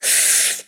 Gesto de mujer que saborea algo agrio
exclamación
mujer
Sonidos: Acciones humanas
Sonidos: Voz humana